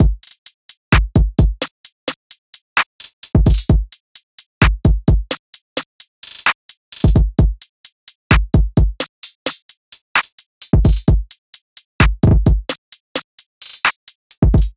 SOUTHSIDE_beat_loop_cut_full_01_130.wav